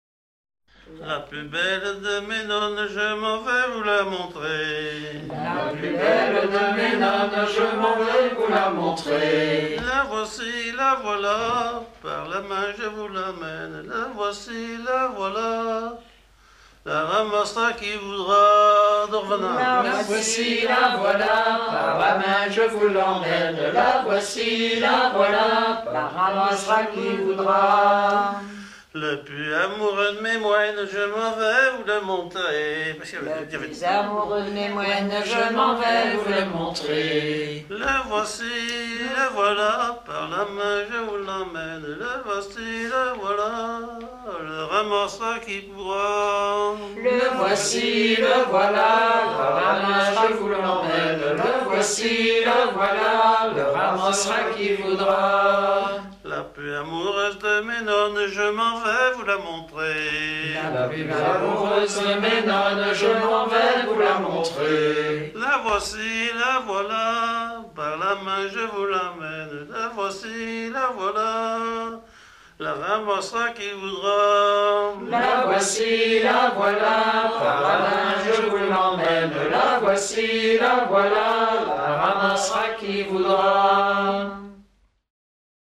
Rondes à baisers et à mariages fictifs
ronde à marier
Pièce musicale éditée